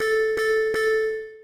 blip_2.ogg